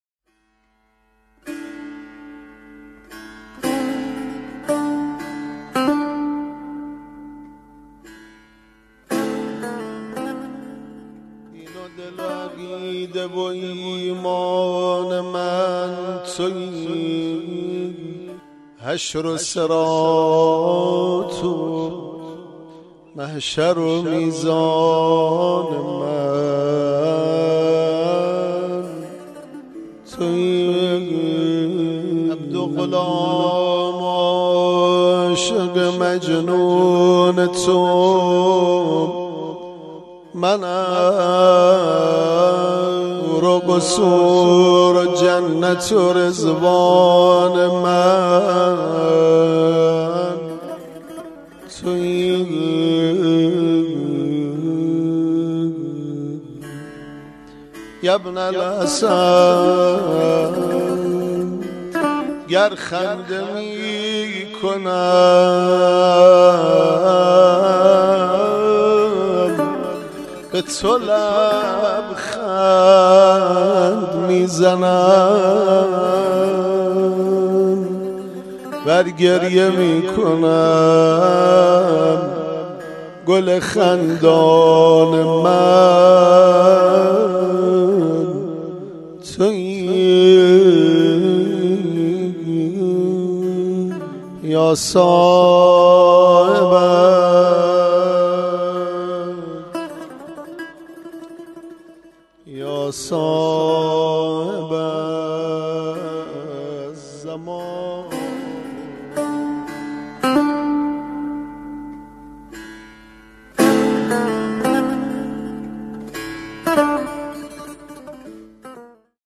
مدح خوانی | دین و دل و عقیده و ایمان من تویی
هیأت بیت الحسین(ع)